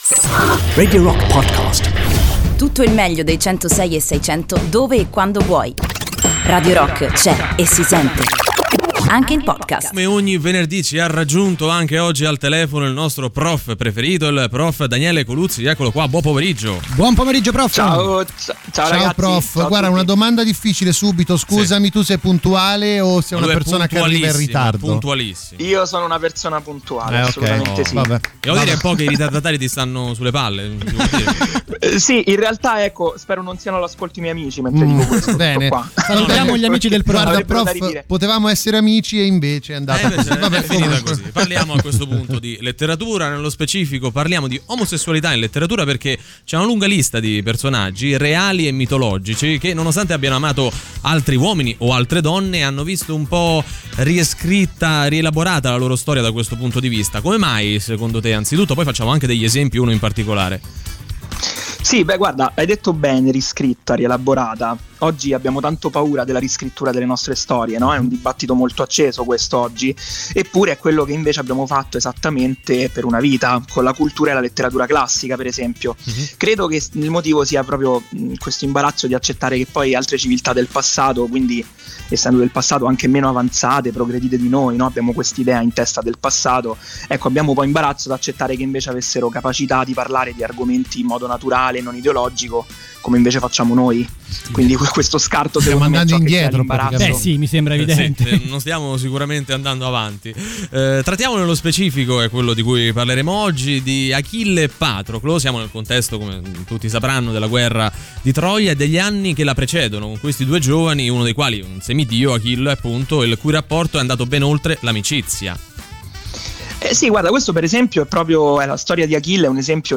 interviene in diretta su Radio Rock, all’interno di “Antipop”, con le sue pillole di Storia e Letteratura.